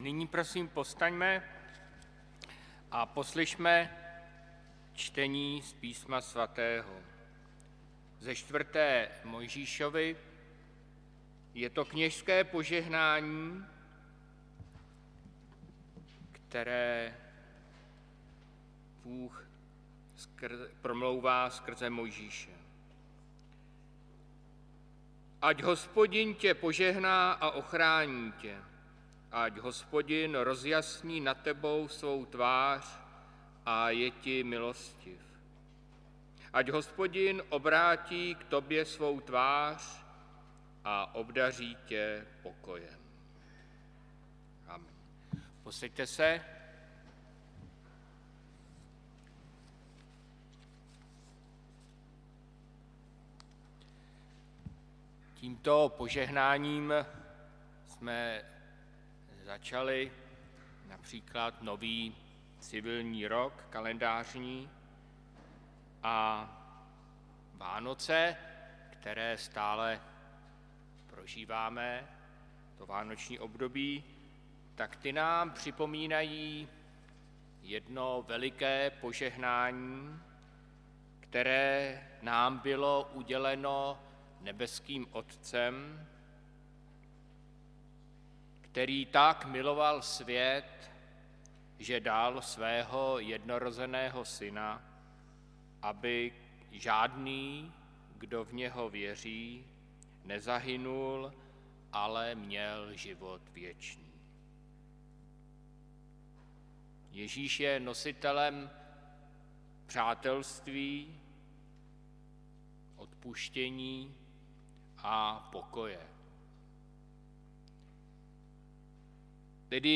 Hudební nešpory 4. 1. 2026 • Farní sbor ČCE Plzeň - západní sbor
pěveckého sboru
varhaníka